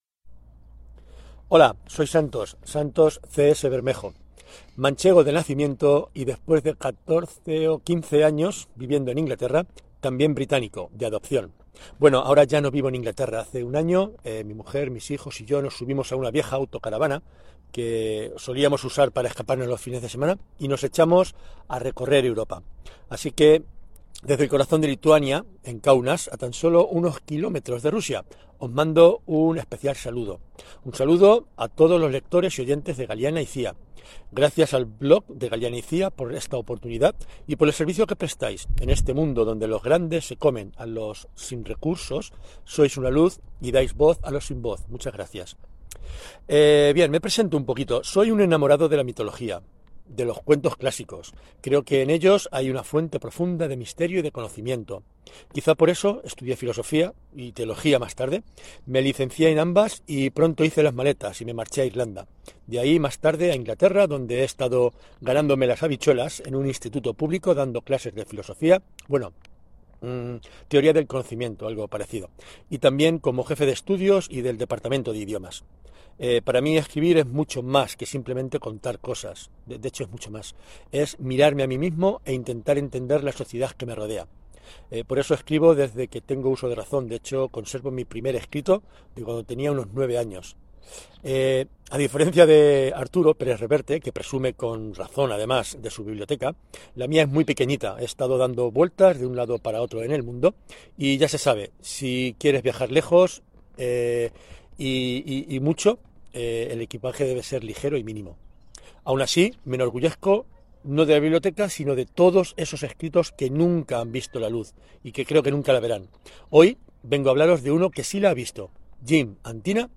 Podcast Literarios